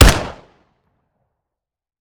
Home gmod sound weapons augolf
weap_augolf_fire_plr_9mm_01.ogg